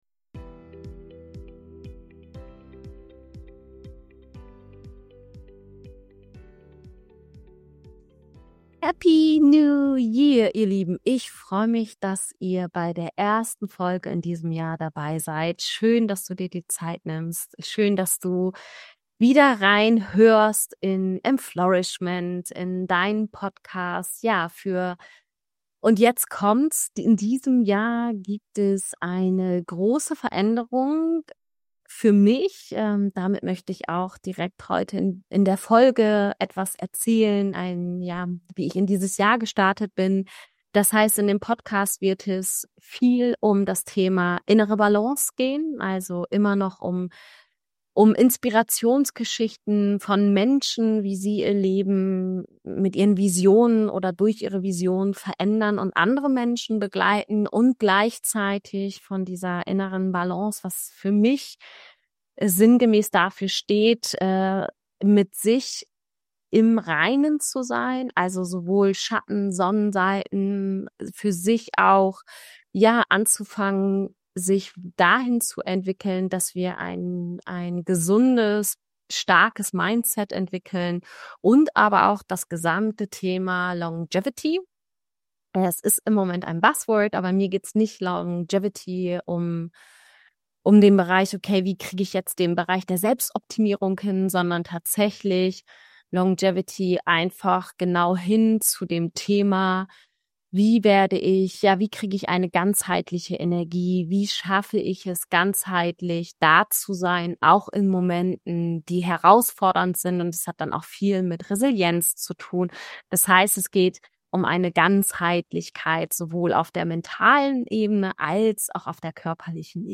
Heute aus Paraguay. alles dreht sich um Neubeginn, Fasten & innere Balance In dieser ersten Solofolge nehme ich dich mit in meinen Jahresstart...